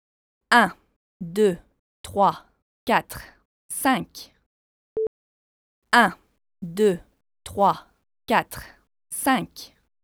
C’est pas de la musique, seulement du vocal pas complexe, le 8 bits doit en est capable…
“a-law” a encore un petit bruit de fuzz …